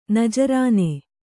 ♪ najarāne